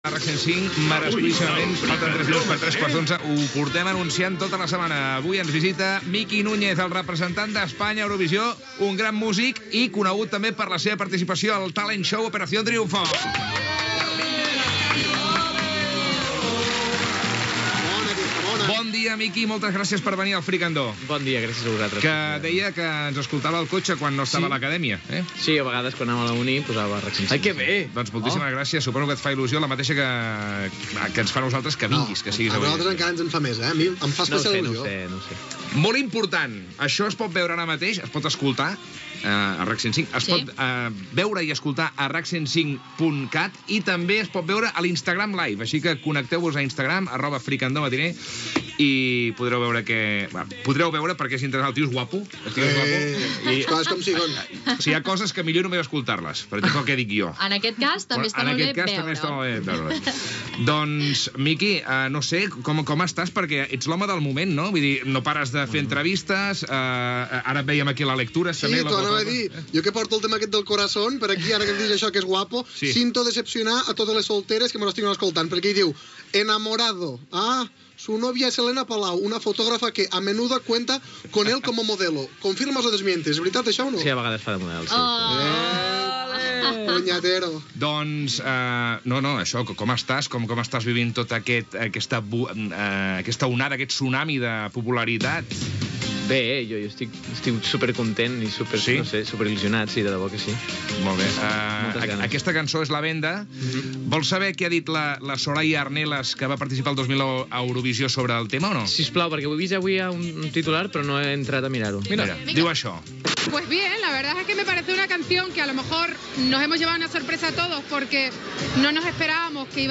Entrevista al cantant Miki Núñez que serà el cantant que representarà a TVE al Festival de la Cançó d'Eurovisió de l'any 2019: comenta el seu pas pel programa "Operación Triumfo" 2018 i la cançó que interpretarà
Entreteniment